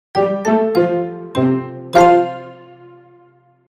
3. Забавный финал для видео